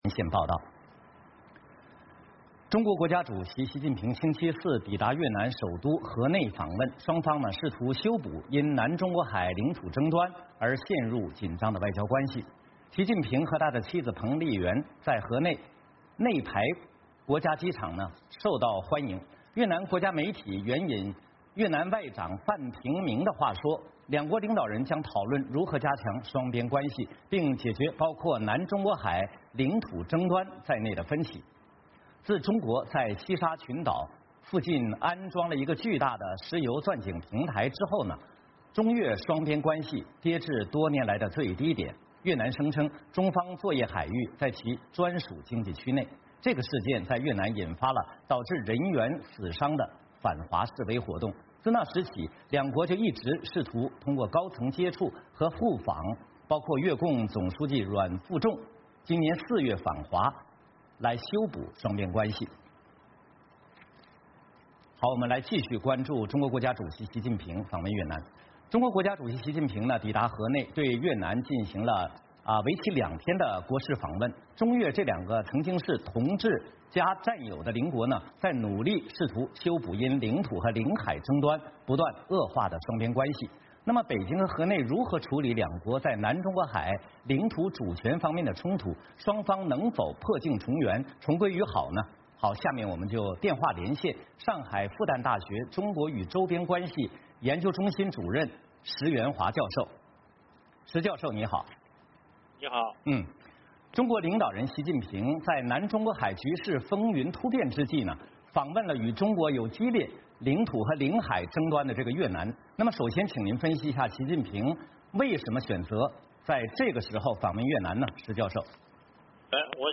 VOA连线：习近平访问越南